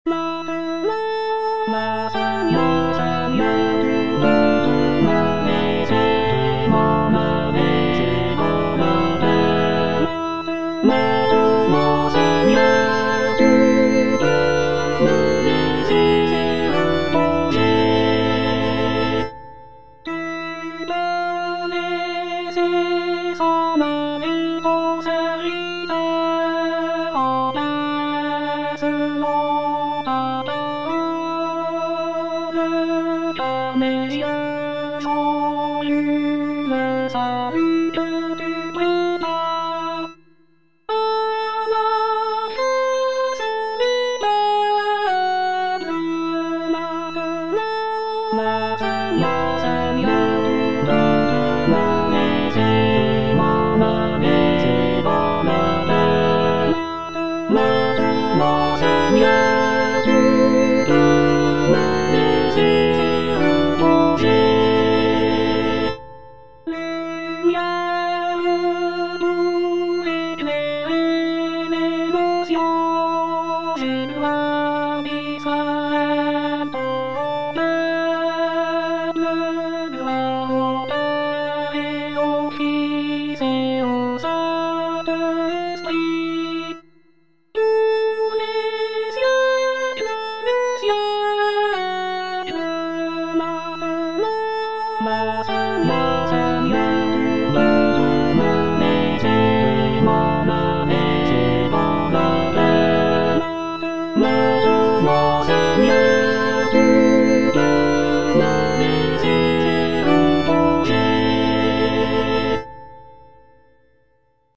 All voices
is a choral composition